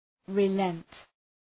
{rı’lent}
relent.mp3